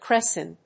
/ˈkrɛsʌn(米国英語), ˈkresʌn(英国英語)/